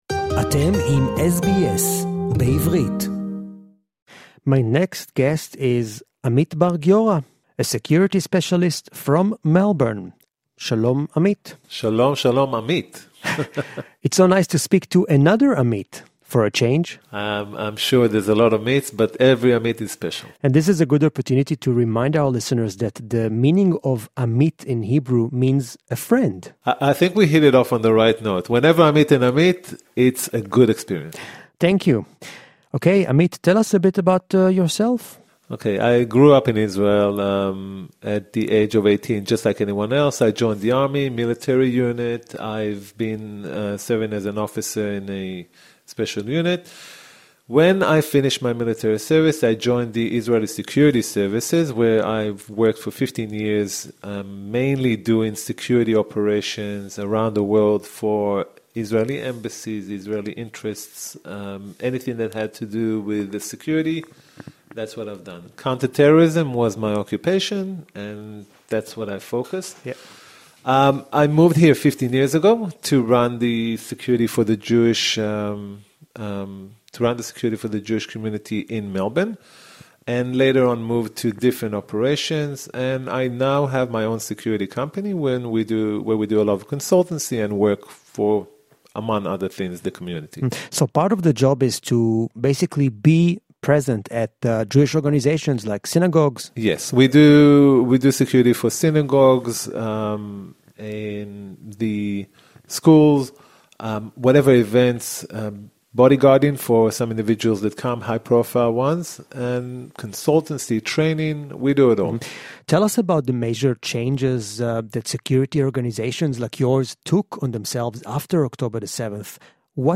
This interview is in English.